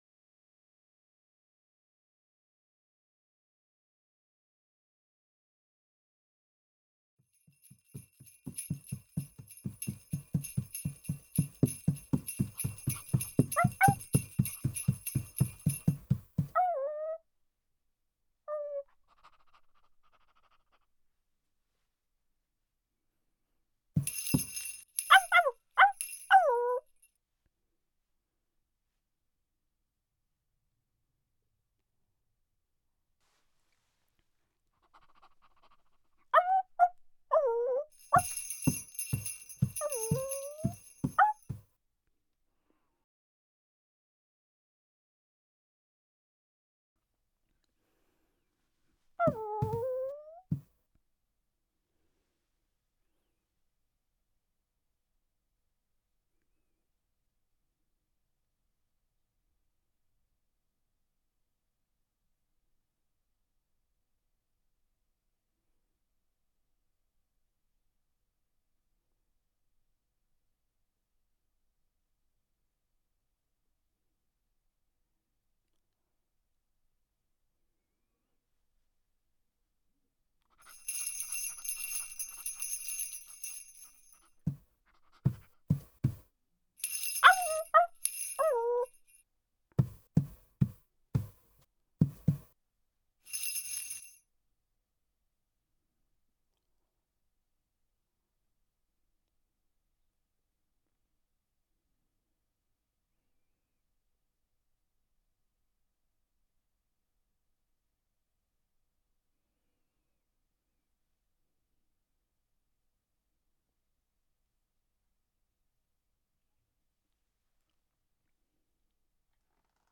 SFX_Scene03_Dog.ogg